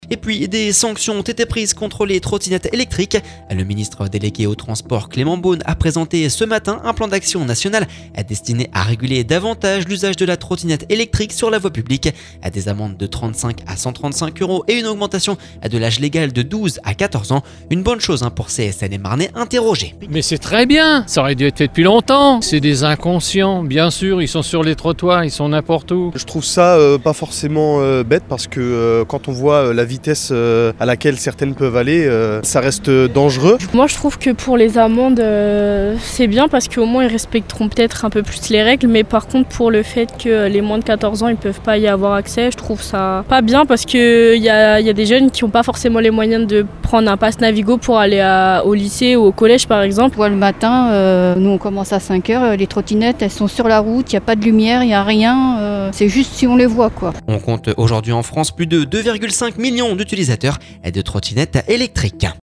Une bonne chose pour ces Seine-et-Marnais interrogés…